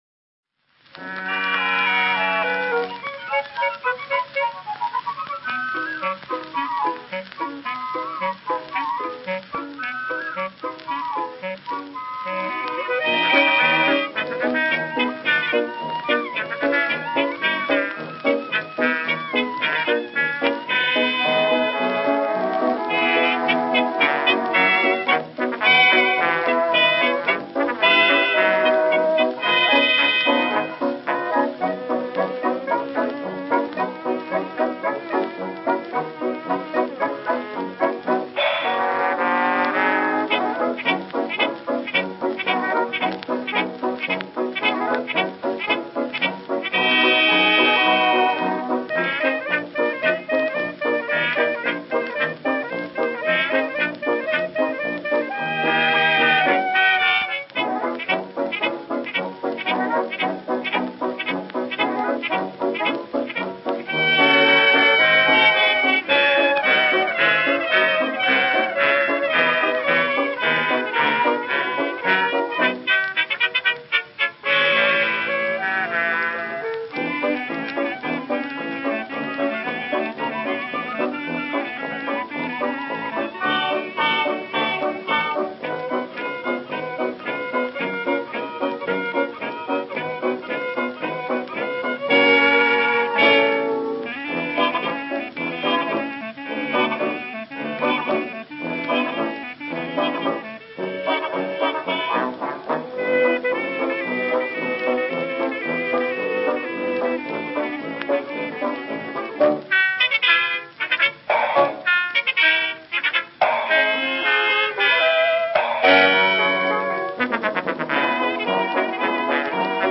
«Чикагская» оркестровая запись